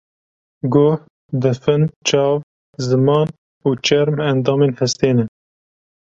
Uitgesproken als (IPA)
/t͡ʃɛɾm/